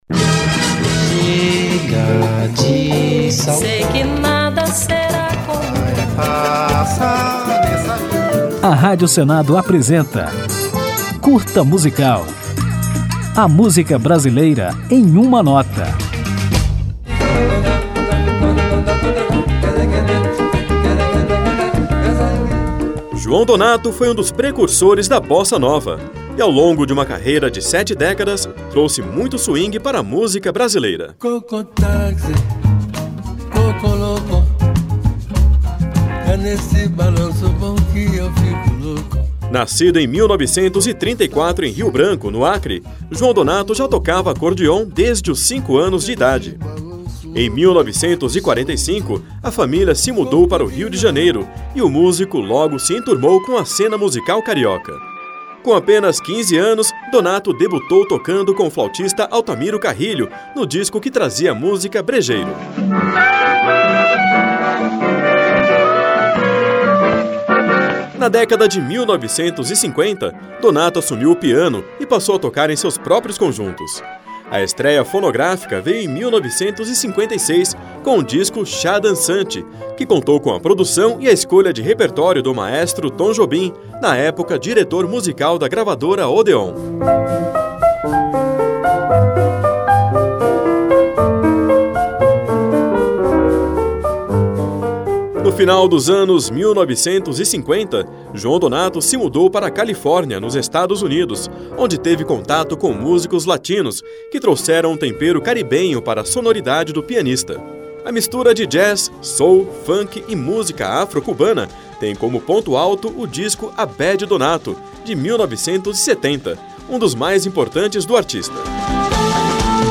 Neste Curta Musical apresentaremos a história do pianista e compositor João Donato, que foi precursor da Bossa Nova e trabalhou com inúmeros artistas, de várias gerações, em uma longa carreira de mais de sete décadas, que só terminou com a morte do músico em 2023. Ao final do programa, ouviremos João Donato na música Bananeira, composição dele em parceria com Gilberto Gil.